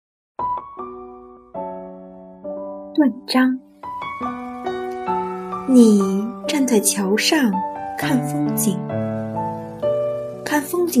九年级语文下册 3《断章》女声配乐朗读（音频素材）